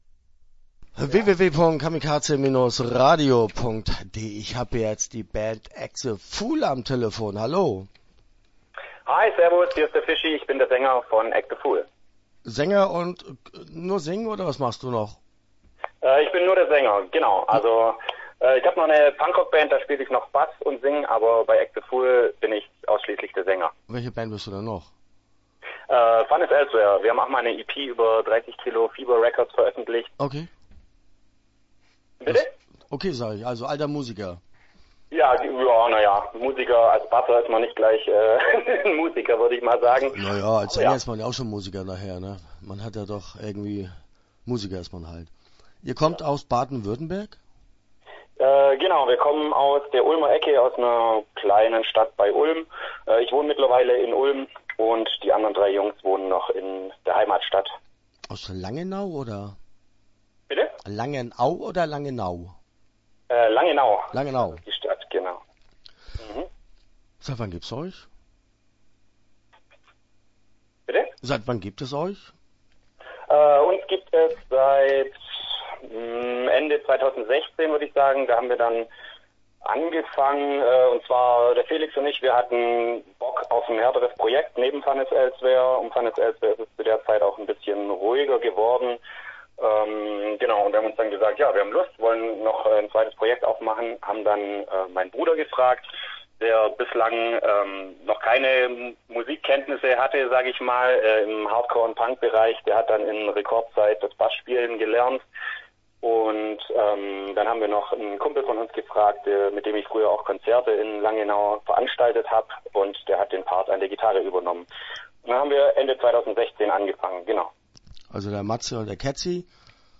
Start » Interviews » Act The Fool